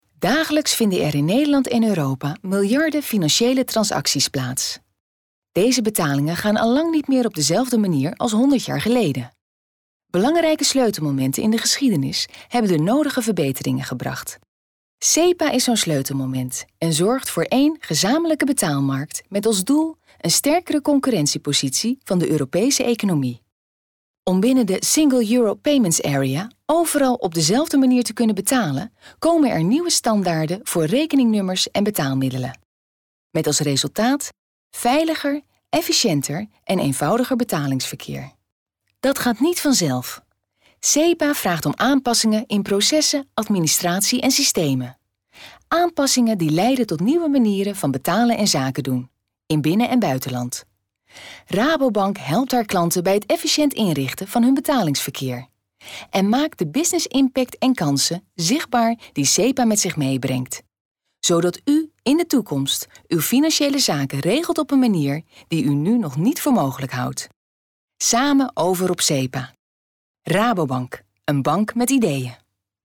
Commercial, Reliable, Friendly, Warm, Corporate
Audio guide
Besides a friendly and warm voice, she can also sound commercial, cheerful, reliable, businesslike, open, informative, fresh, recognizable, sultry and clear.